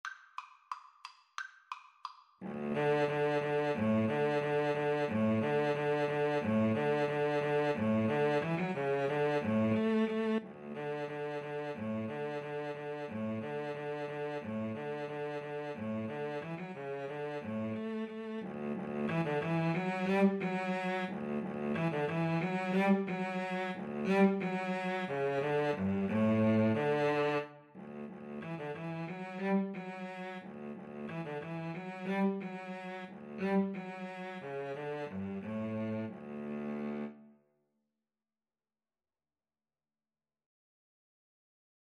Play (or use space bar on your keyboard) Pause Music Playalong - Player 1 Accompaniment transpose reset tempo print settings full screen
D major (Sounding Pitch) (View more D major Music for Violin-Cello Duet )
=180 Vivace (View more music marked Vivace)
Classical (View more Classical Violin-Cello Duet Music)